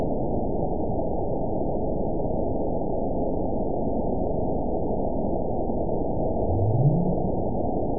event 920506 date 03/28/24 time 13:09:09 GMT (1 year, 1 month ago) score 9.68 location TSS-AB01 detected by nrw target species NRW annotations +NRW Spectrogram: Frequency (kHz) vs. Time (s) audio not available .wav